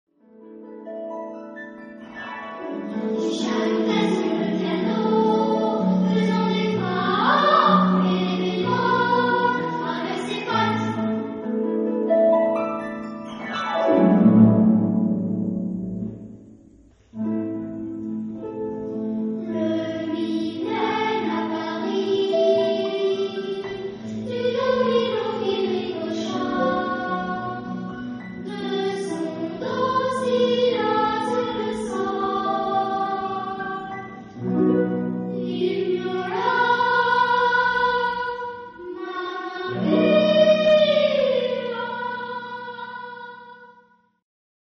Type of material: Choral score with piano
Genre-Style-Form: Children's song
Mood of the piece: fast ; rhythmic ; binary
Type of Choir: S  (1 children voices )
Instrumentation: Piano  (1 instrumental part(s))
Tonality: A flat major ; A major ; E minor